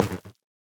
Minecraft Version Minecraft Version latest Latest Release | Latest Snapshot latest / assets / minecraft / sounds / block / fungus / break2.ogg Compare With Compare With Latest Release | Latest Snapshot